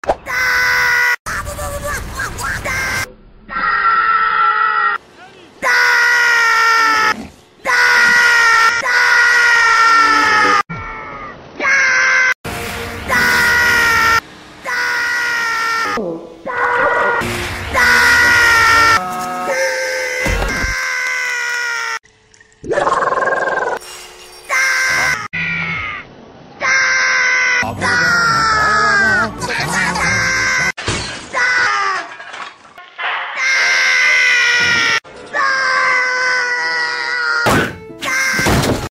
Rabbids Screams DAAAAHH
Rabbids-Screams-DAAAAHH.mp3